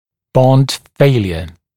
[bɔnd ‘feɪljə][бонд ‘фэйлйэ]нежелательное отклеивание